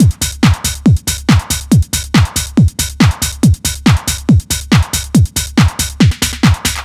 NRG 4 On The Floor 018.wav